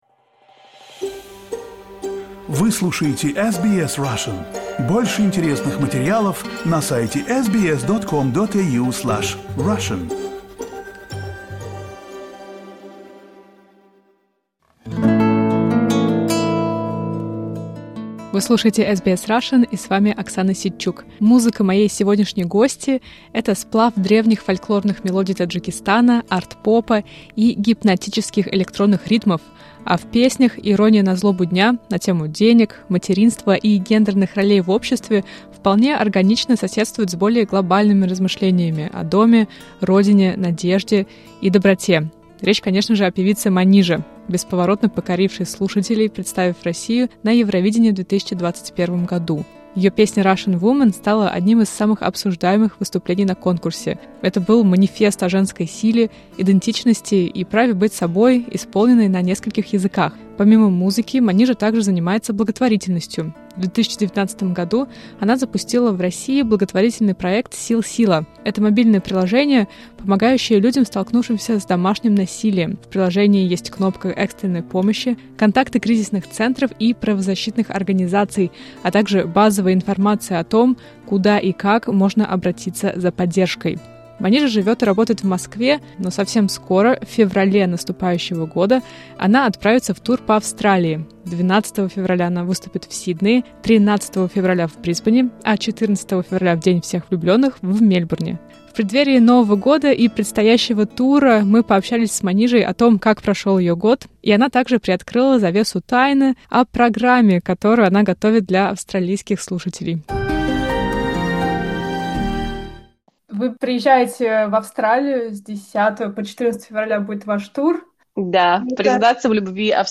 В преддверии австралийского тура мы поговорили с певицей Манижей, представлявшей Россию на «Евровидении» в 2021 году, об иммиграции, ощущении дома, благотворительности, силе фольклора и музыки. Манижа также рассказала, каким был для нее 2025 год, и о программе, которую она готовит для австралийских слушателей.